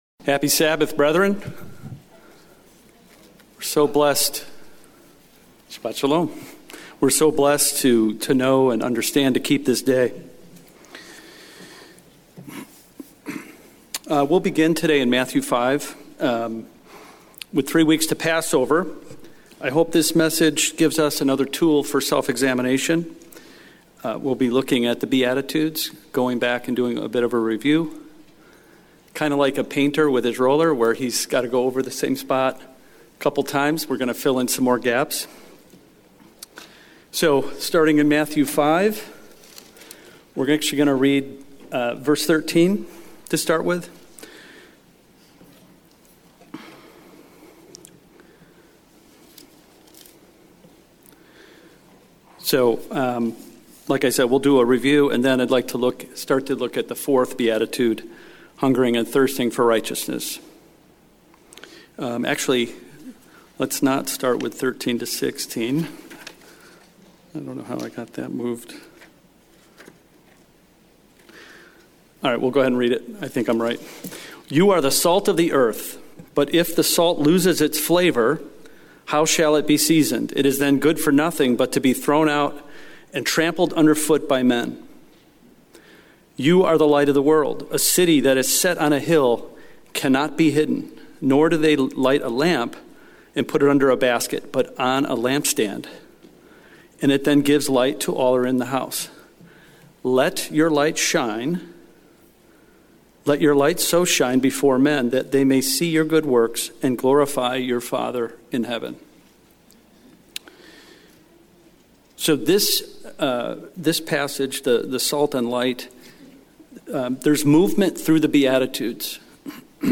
sermon
Given in Houston, TX